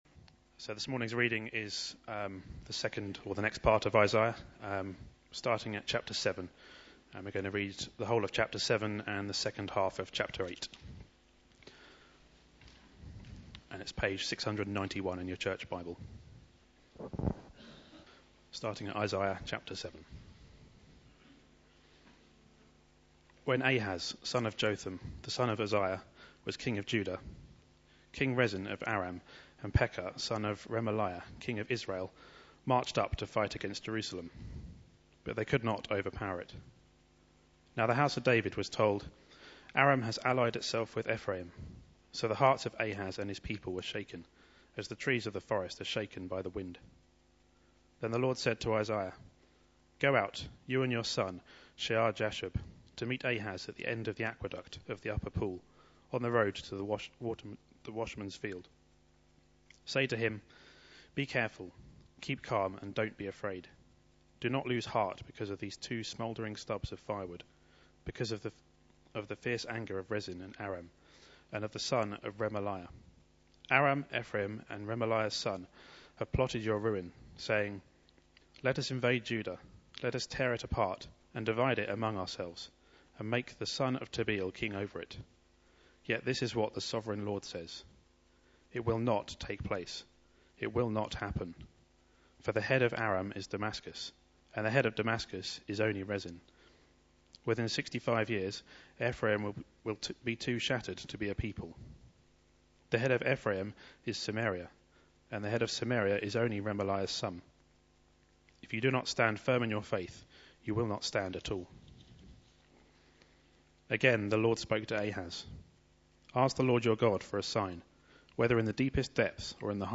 Media Library Media for Sunday Service on Sun 23rd Sep 2012 11:00 Speaker